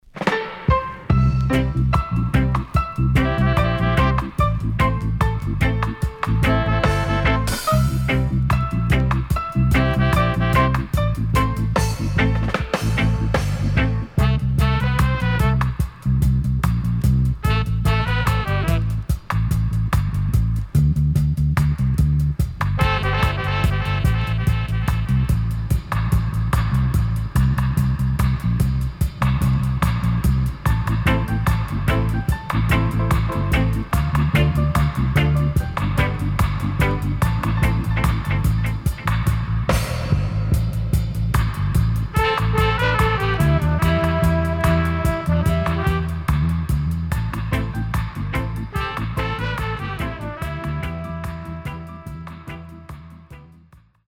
SIDE B:少しチリノイズ入りますが良好です。